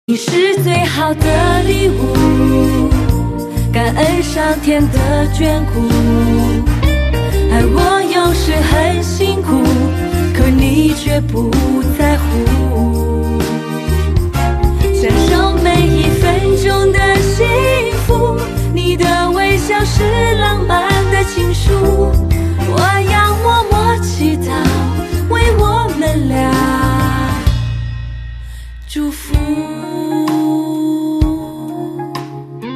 M4R铃声, MP3铃声, 华语歌曲 28 首发日期：2018-05-15 12:04 星期二